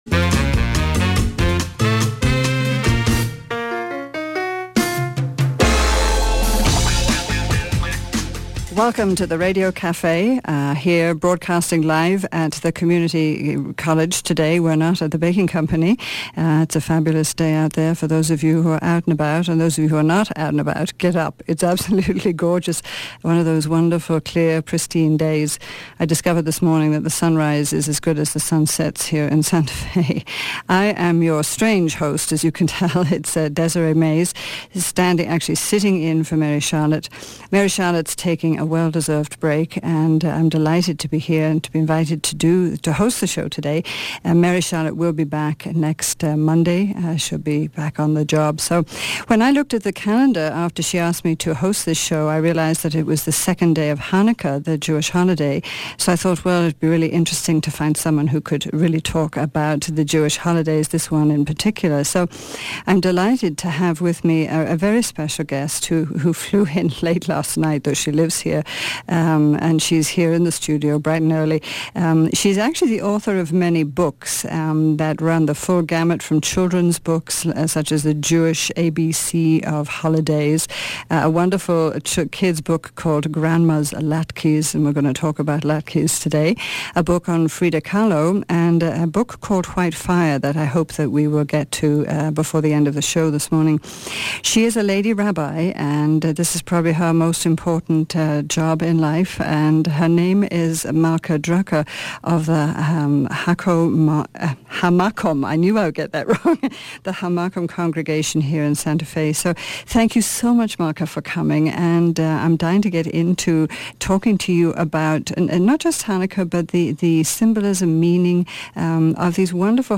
Radio Cafe Interview